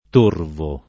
t1rvo o t0rvo] agg. — parola entrata in it. fin dal ’300 come riproduz. dòtta del lat. torvus, quindi con un -o- aperto (che tale sarebbe anche se la parola non fosse dòtta, rispondendo a un -o- lat. breve per natura); e attestata sempre con -o- aperto fin quasi alla metà dell’800: pn. conservata ancor oggi in diverse regioni settentr. e meridionali — preval. -o- chiuso nella Tosc. (di più in quella di ponente) e nell’It. centr., prob. per attraz. di torbo, a cui torvo si avvicina non soltanto per il suono ma anche per quel tanto che sembra avere d’oscuro e d’odioso nel suo sign. generale — cfr. torpido